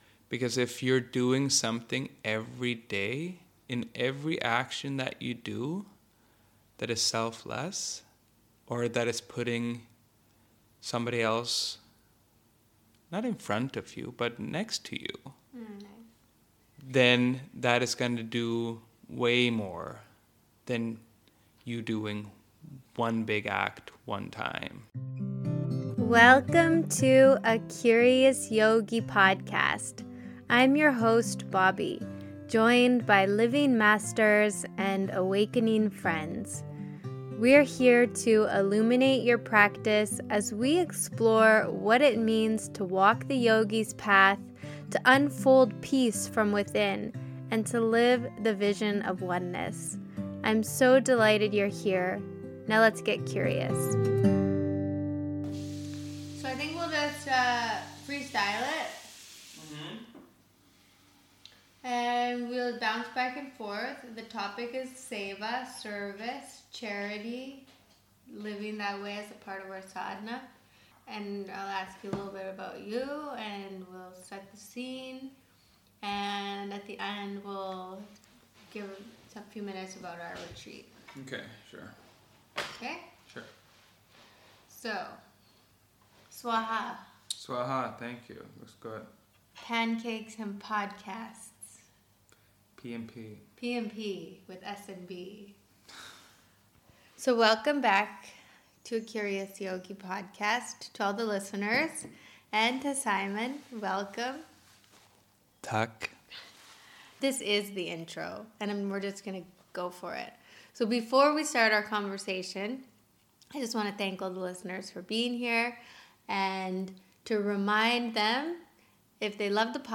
Join me weekly for joyful conversations with wise teachers, sincere yogis and other spiritual seekers. We inquire into our deepest questions and learn how to apply the ancient wisdom into our own daily practice…and life.